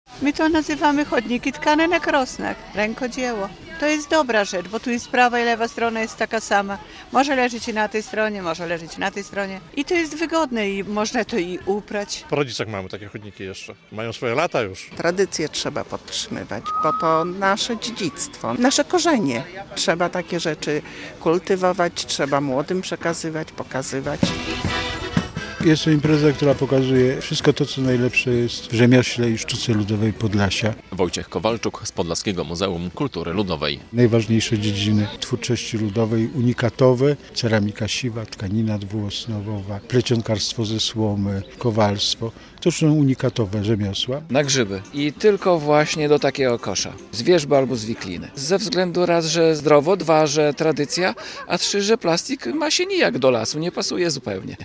Targi Sztuki Ludowej Podlasia w Podlaskim Muzeum Kultury Ludowej w Wasilkowie - relacja